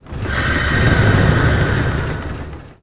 Rumbling Sound
轰隆轰隆